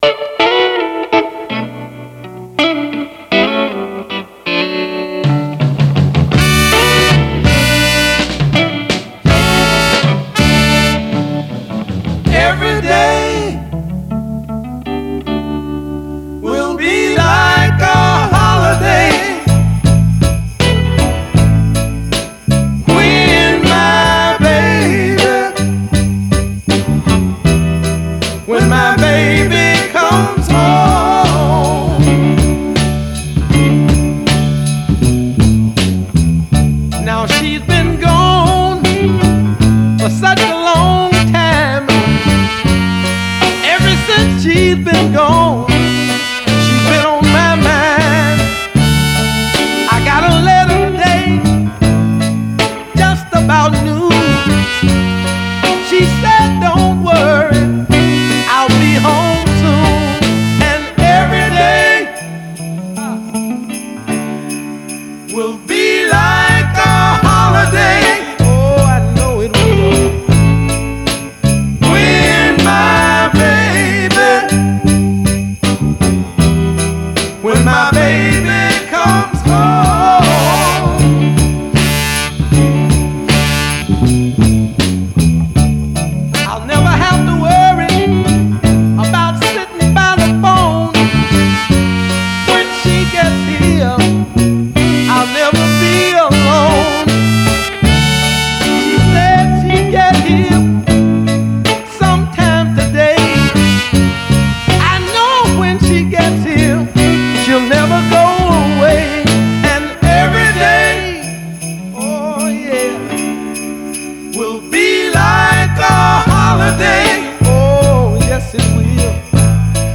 Genero: Blues